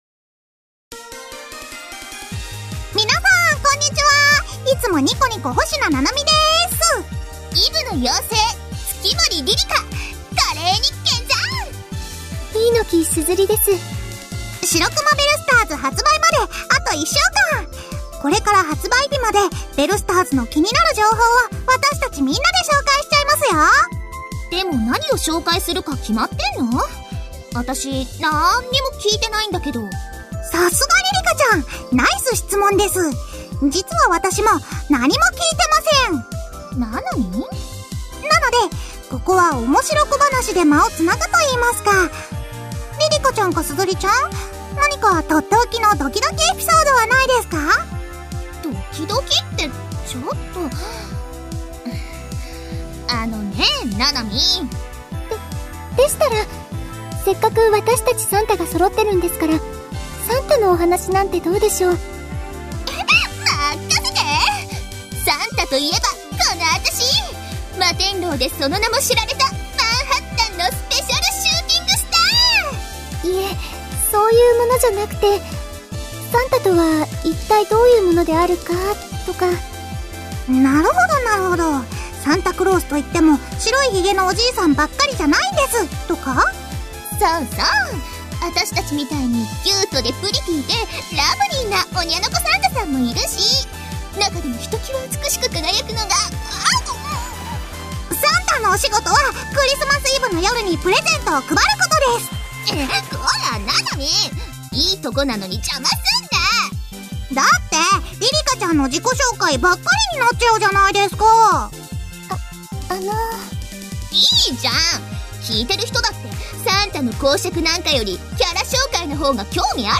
カウントダウンボイスが出そろったので、まとめてみた。